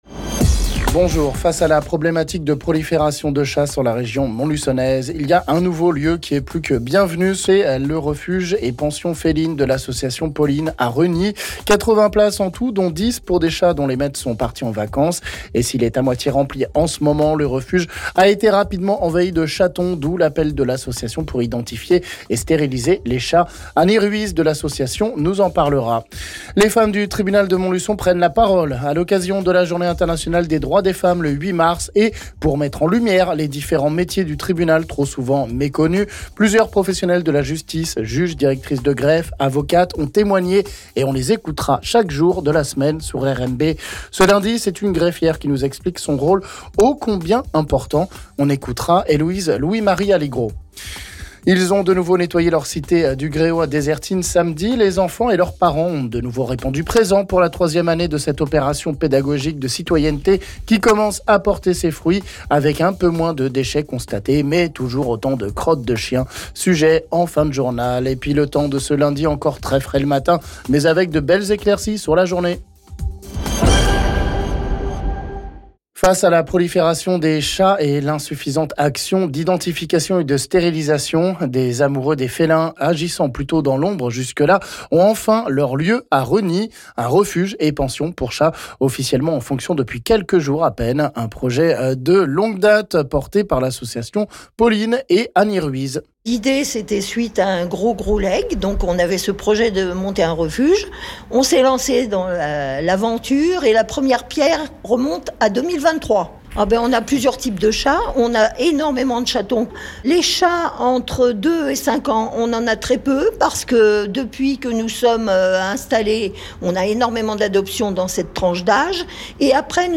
Ce lundi c’est une greffière qui nous explique son rôle au combien important.